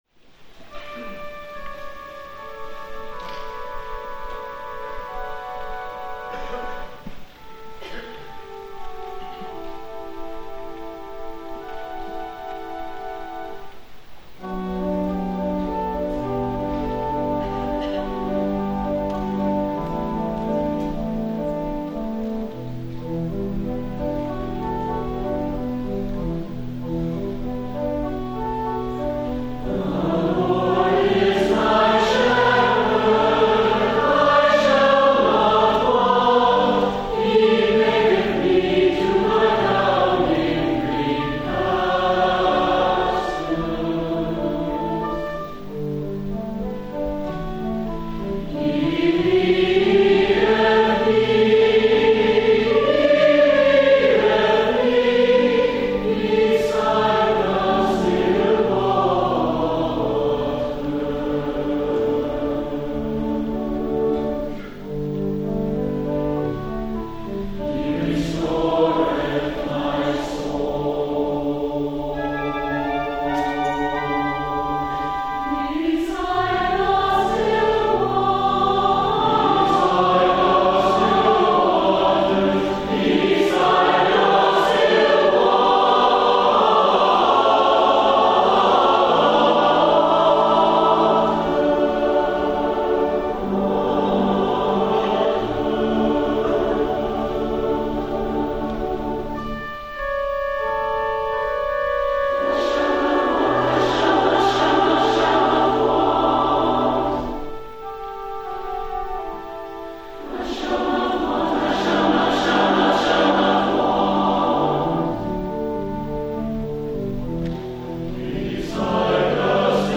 for SATB Chorus and Organ (1998)